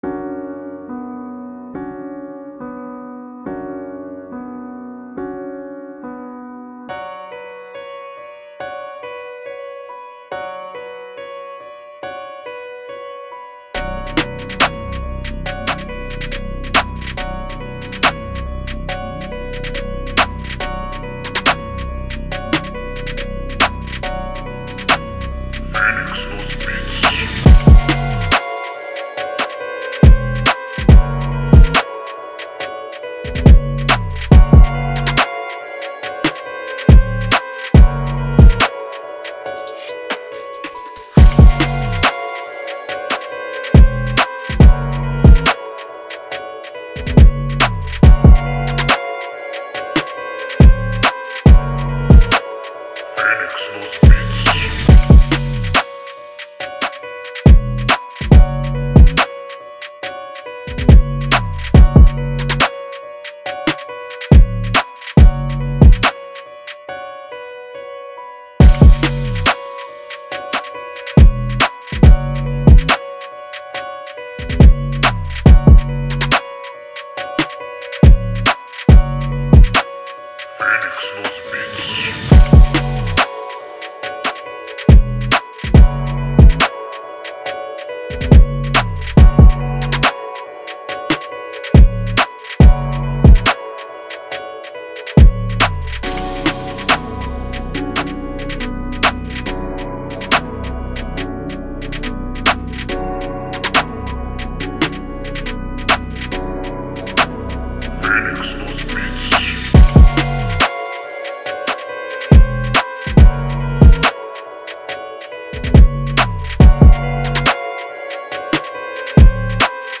Dark Drill Instrumental